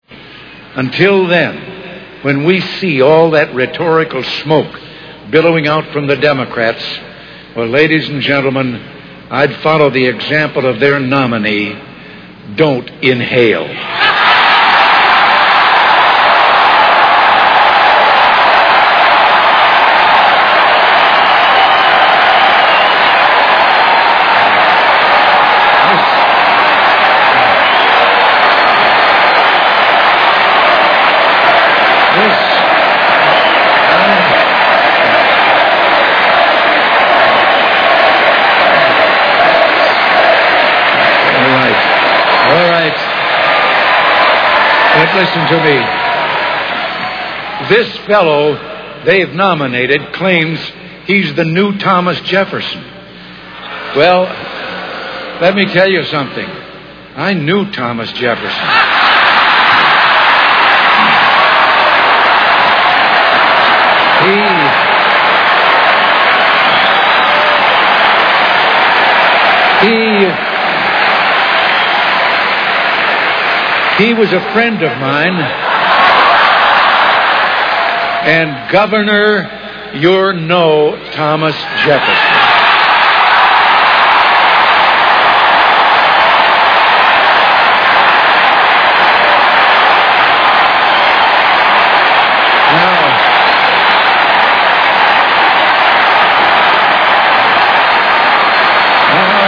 Reagan 1992 at the republican convention. For those of you who will miss this reference, Clinton was asked about drug use and he said he tried marijuana but did not inhale.